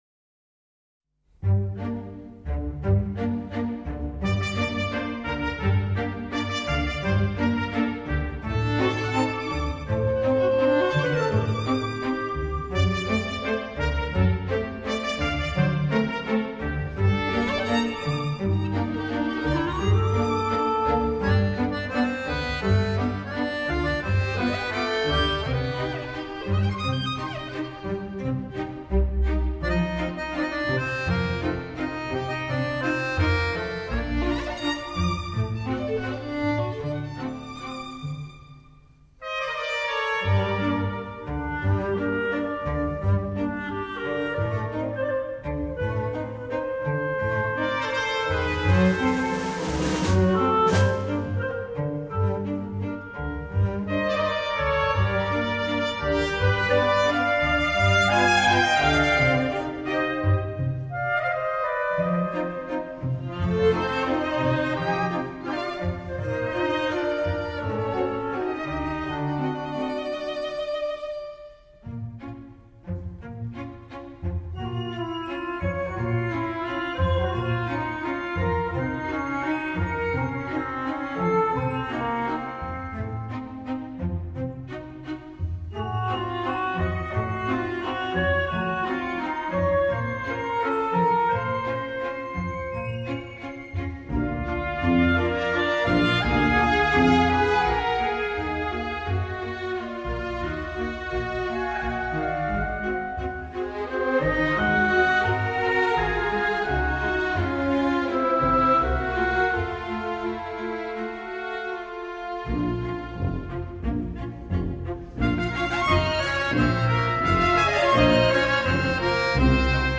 Genre: Score